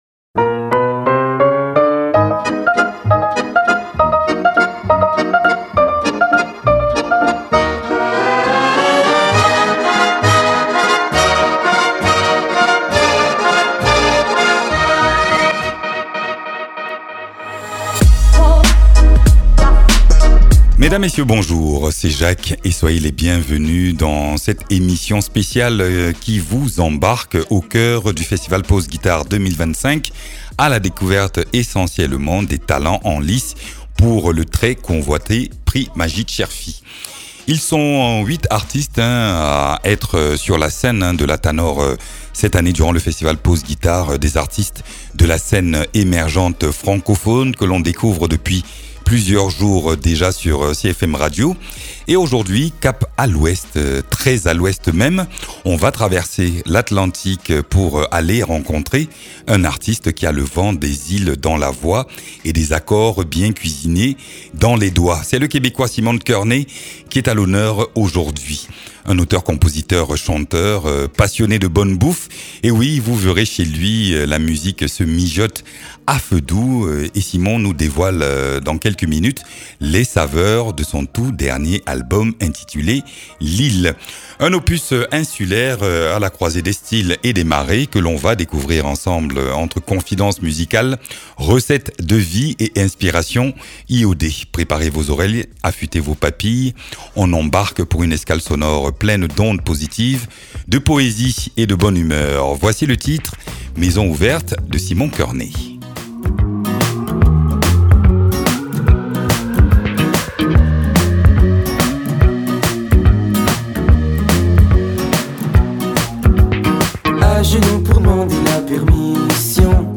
Un moment chaleureux, un brin décalé, comme lui.
auteur-compositeur-interprète.